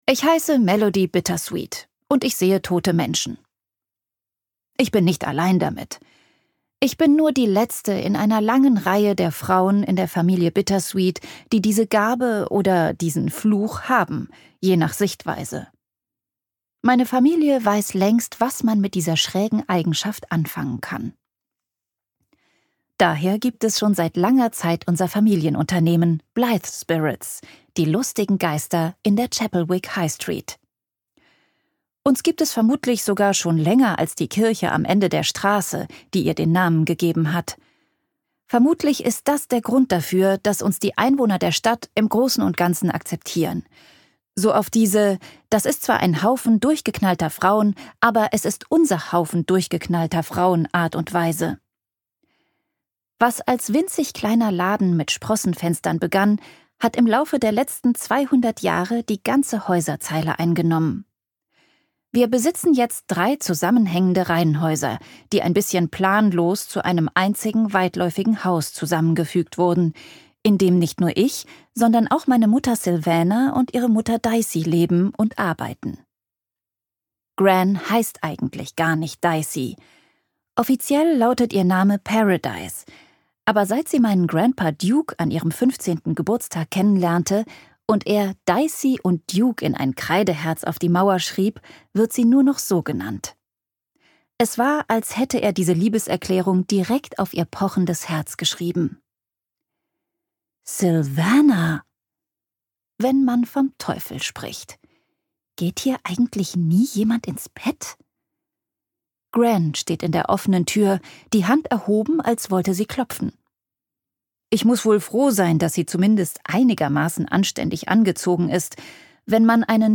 The Girls Ghostbusting Agency – Crazy Spooky Love - Josie Silver | argon hörbuch
Gekürzt Autorisierte, d.h. von Autor:innen und / oder Verlagen freigegebene, bearbeitete Fassung.